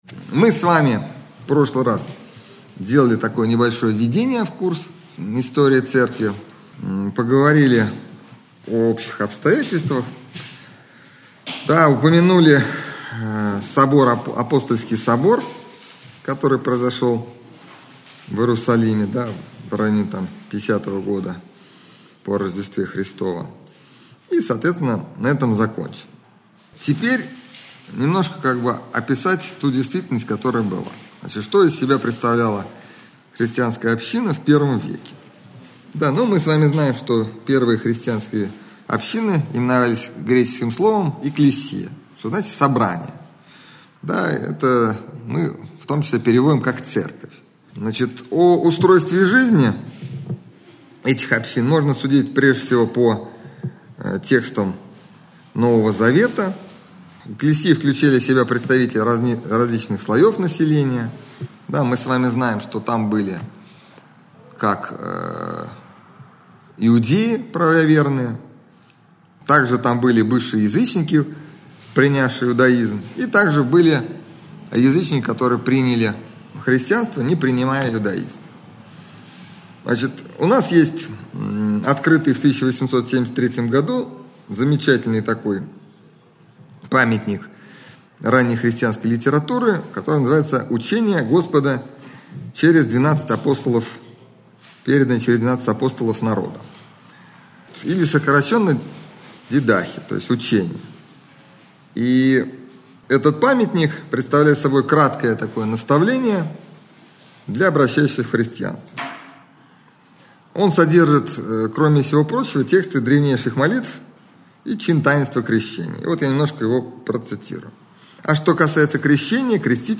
лекция
Общедоступный православный лекторий 2013-2014